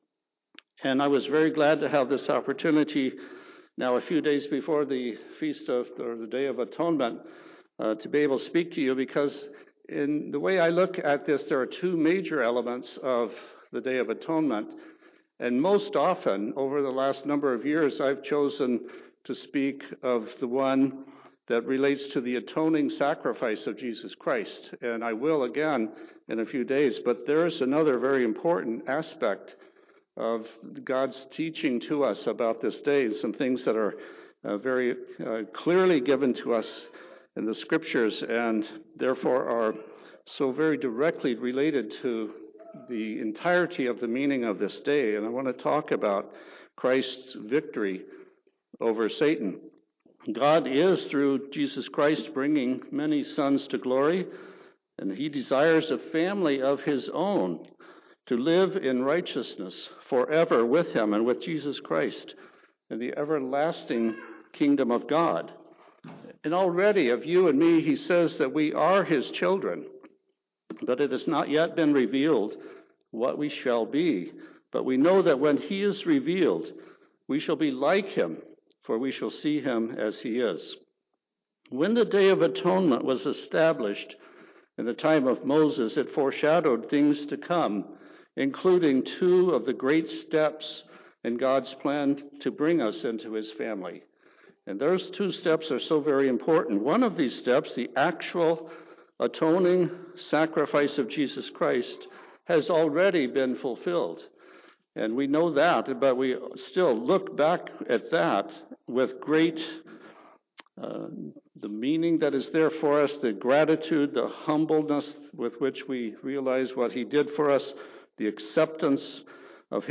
In this sermon it is shown from the Scriptures that this is about Christ's victory over Satan.
Given in Tacoma, WA